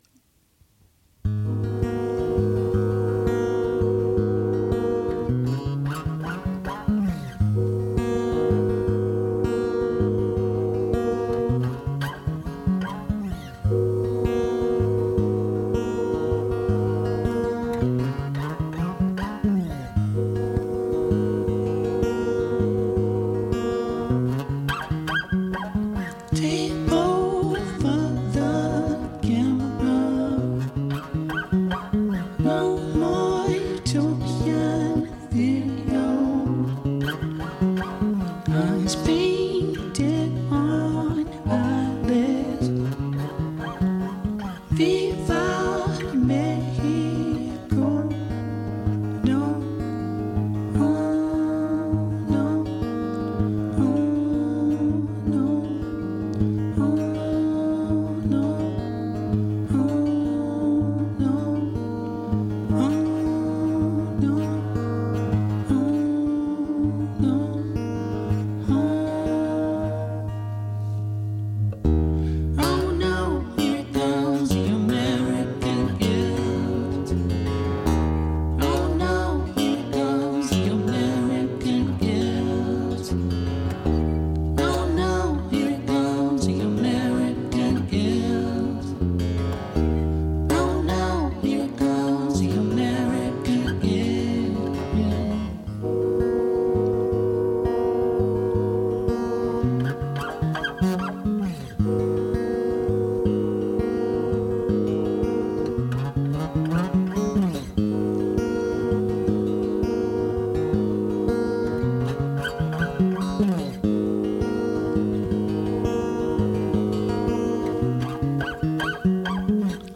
acoustic session broadcast live